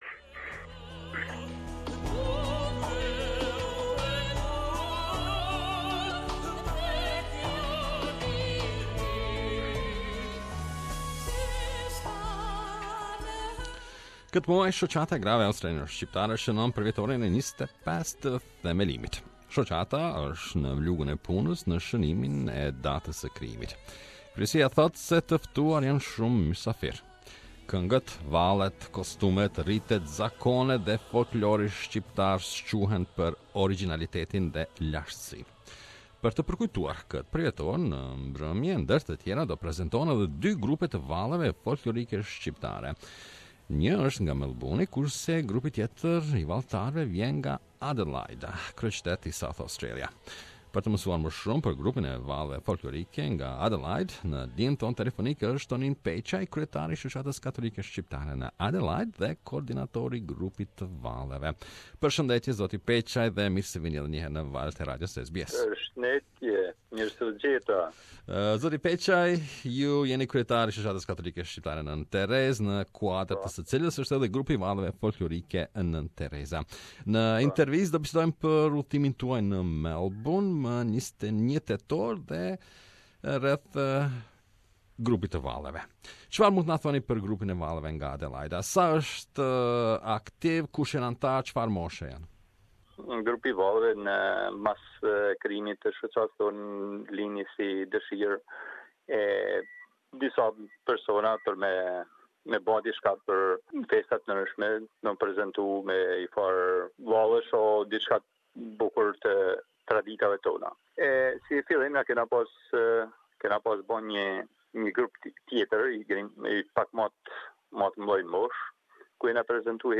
The Adelaide Albanian Folk Dance Group has been invited by the Australian Women's Association in Melbourne to be part of the 25th anniversary celebration of the Albanian Women's Association. We interviewed